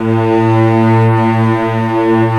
Index of /90_sSampleCDs/Roland LCDP13 String Sections/STR_Combos 2/CMB_Str.Orch Oct